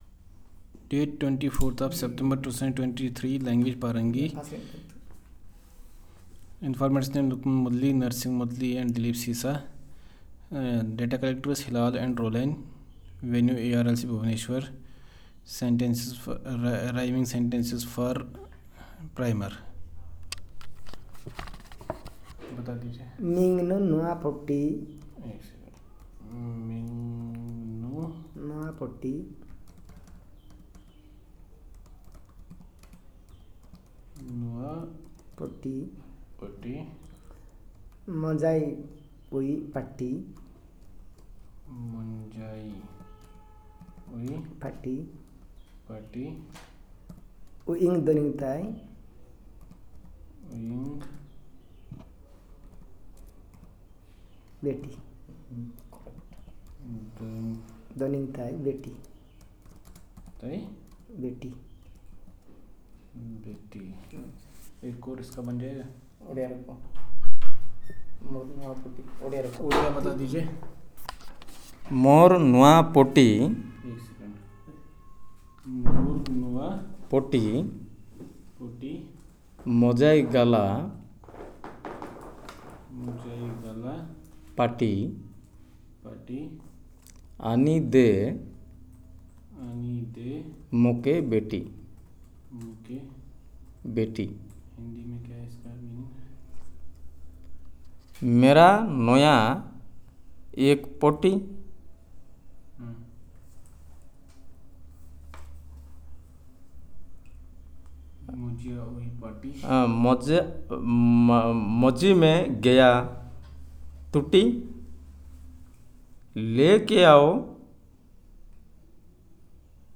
Elicitation of rhyming sentences for Primer